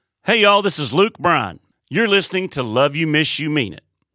LINER Luke Bryan (LYMYMI) 4